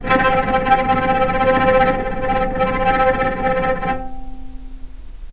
healing-soundscapes/Sound Banks/HSS_OP_Pack/Strings/cello/tremolo/vc_trm-C4-pp.aif at f6aadab7241c7d7839cda3a5e6764c47edbe7bf2
vc_trm-C4-pp.aif